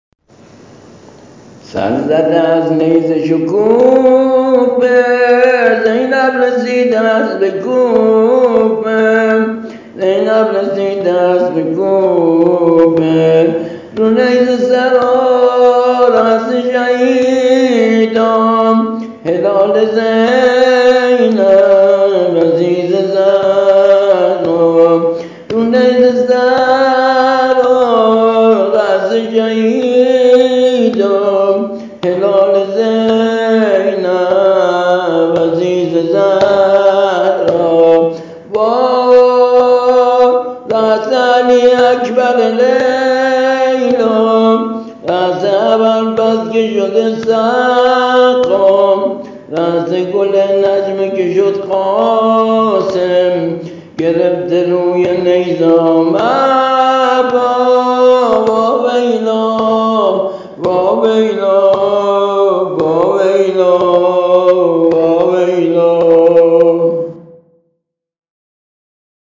◾سبک و ملودی جدید